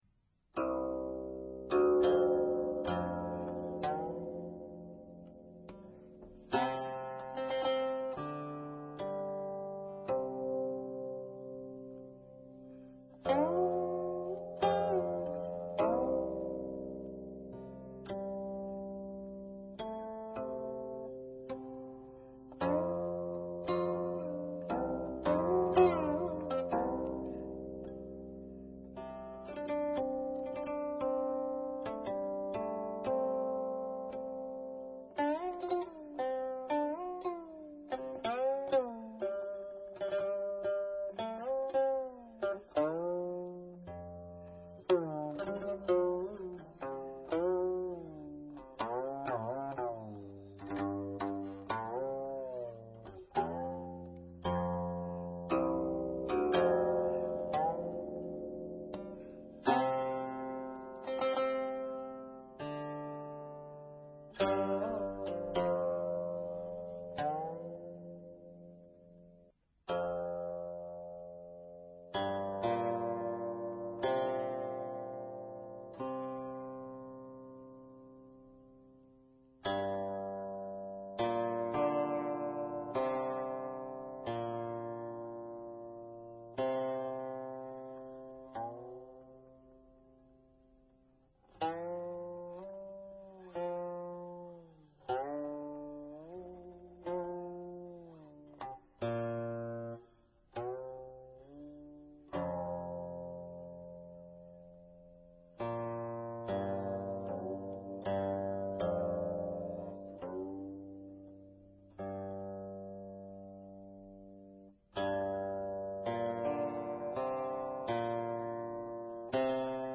guqin(1).mp3